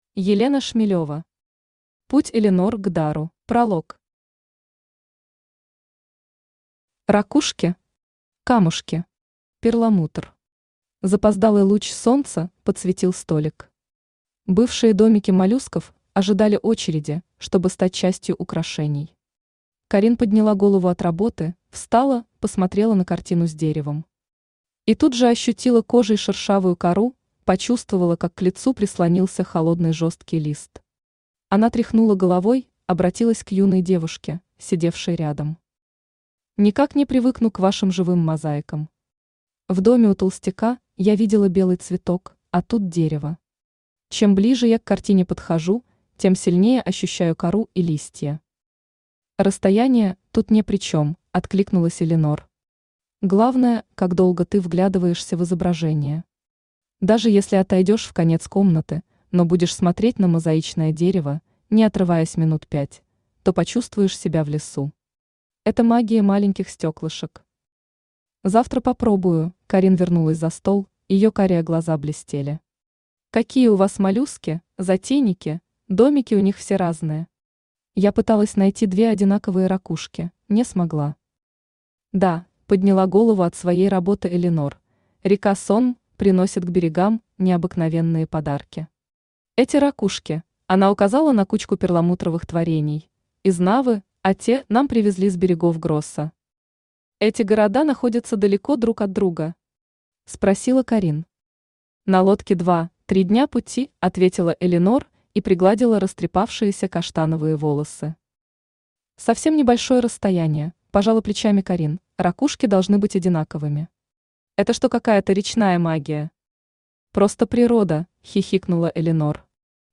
Аудиокнига Путь Элинор к Дару | Библиотека аудиокниг
Aудиокнига Путь Элинор к Дару Автор Елена Шмелёва Читает аудиокнигу Авточтец ЛитРес.